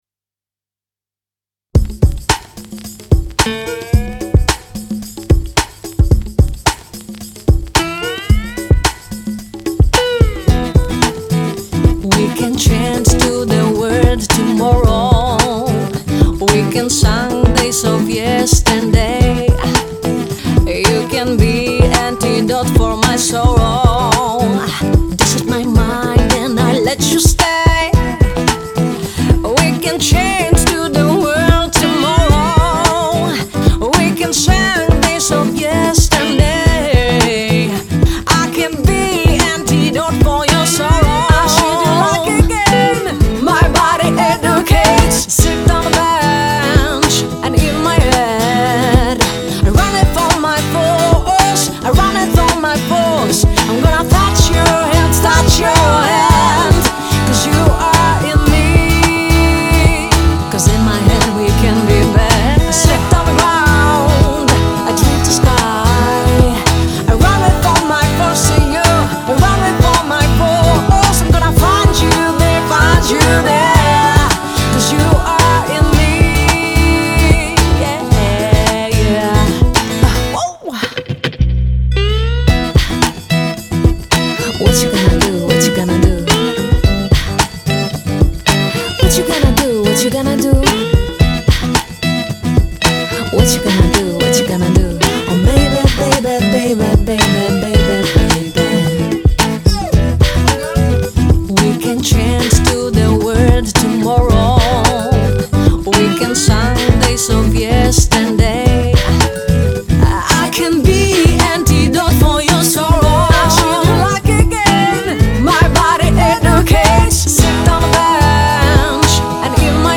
• Genre: Indie / Pop